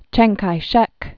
(chăng kīshĕk, jyäng) 1887-1975.